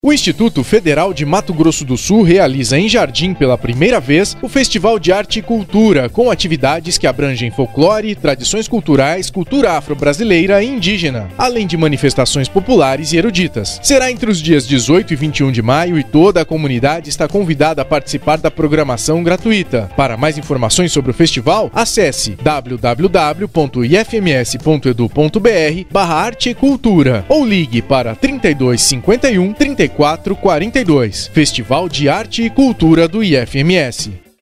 Áudio enviado às rádios para divulgação do Festival de Arte e Cultura.